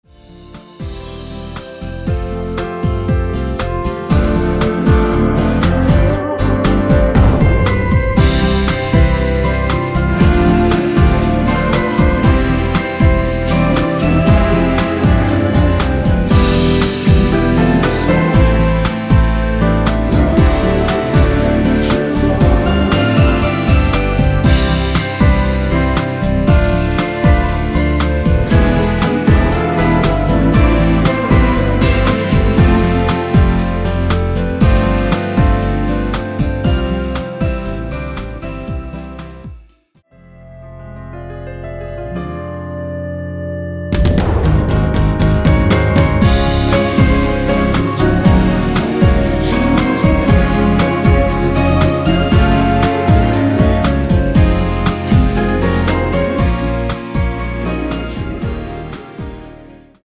원키에서(-3)내린 멜로디와 코러스 포함된 MR 입니다.(미리듣기 참조)
노래방에서 노래를 부르실때 노래 부분에 가이드 멜로디가 따라 나와서
앞부분30초, 뒷부분30초씩 편집해서 올려 드리고 있습니다.
중간에 음이 끈어지고 다시 나오는 이유는